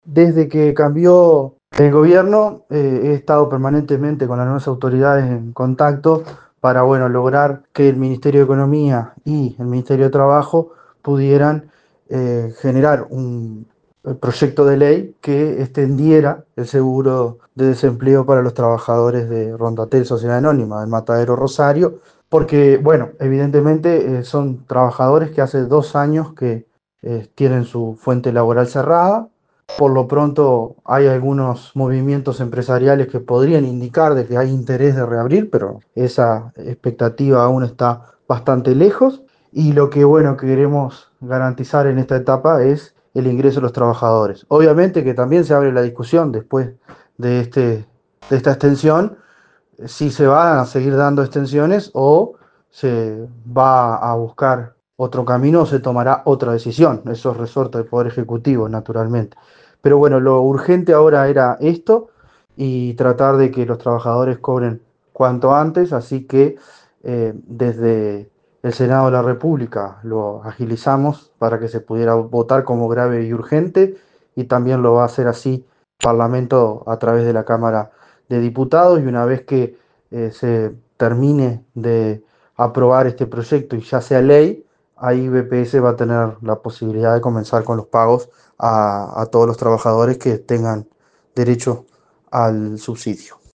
Habla el diputado Nicolás Viera.